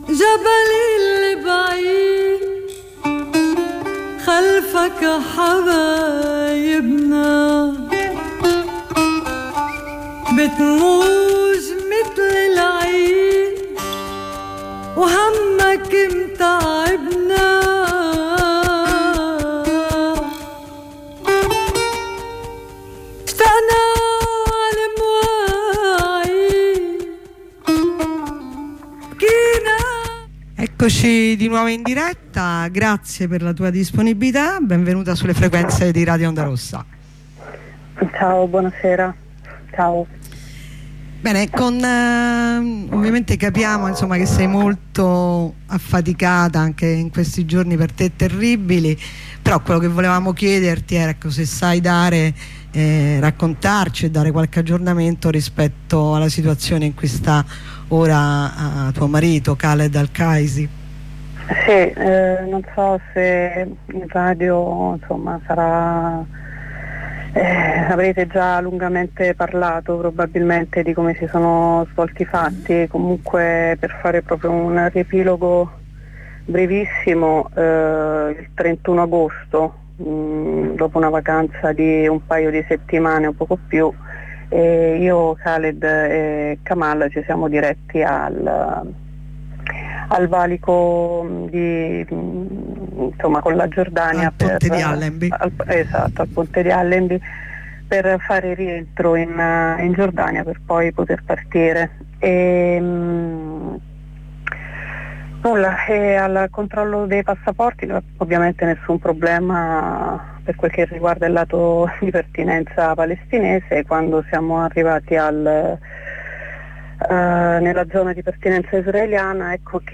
Interviene una compagna sulla manifestazione di Milano